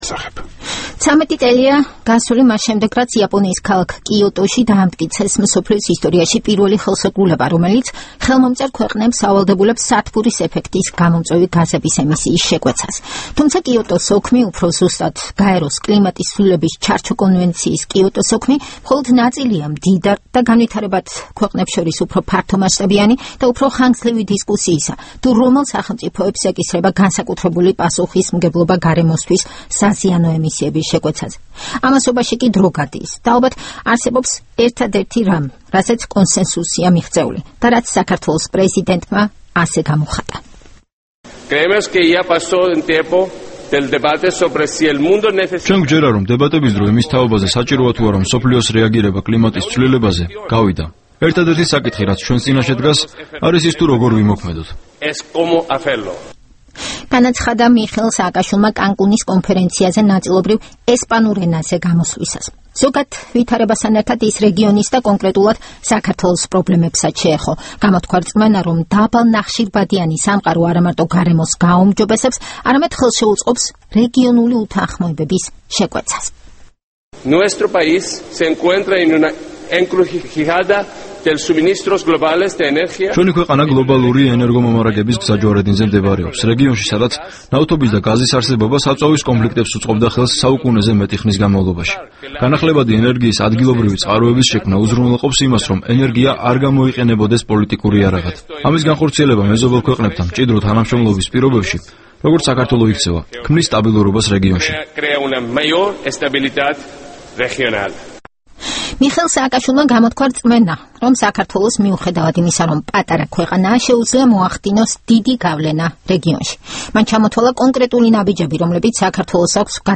საქართველოს პრეზიდენტის გამოსვლა კანკუნის კონფერენციაზე